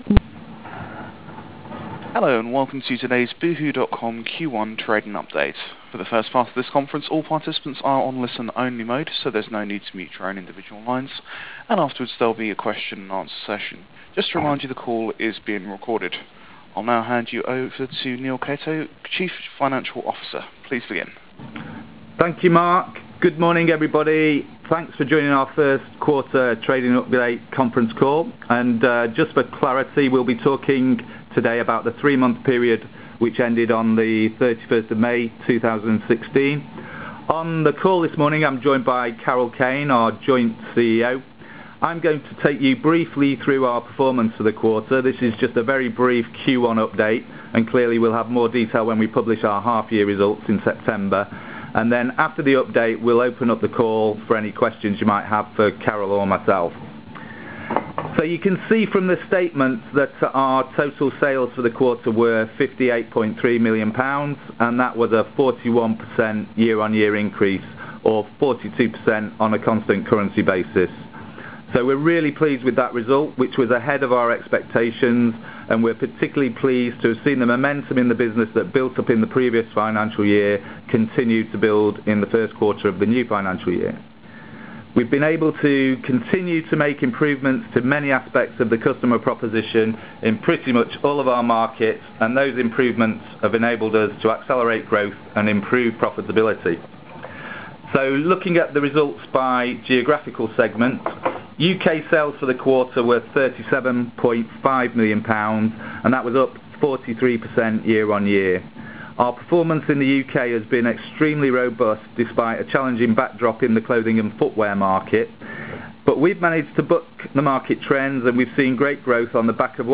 conf-call-08-june-16.wav